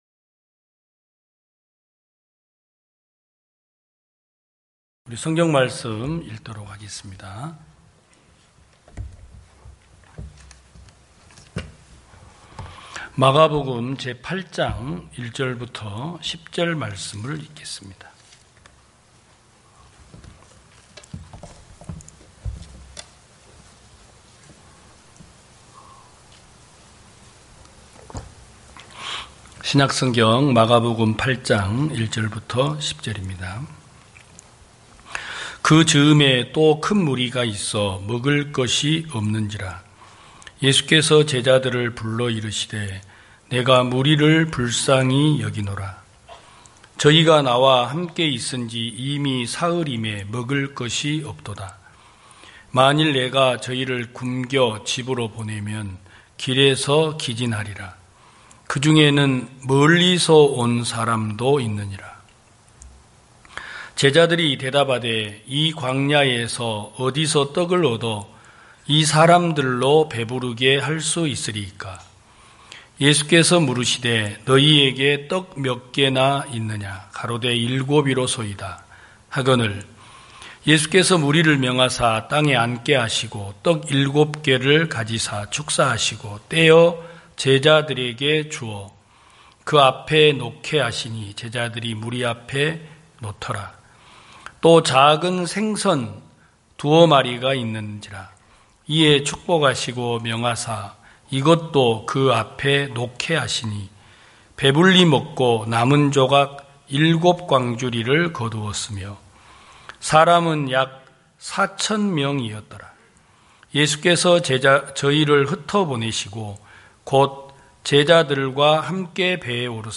2021년 08월 01일 기쁜소식부산대연교회 주일오전예배
성도들이 모두 교회에 모여 말씀을 듣는 주일 예배의 설교는, 한 주간 우리 마음을 채웠던 생각을 내려두고 하나님의 말씀으로 가득 채우는 시간입니다.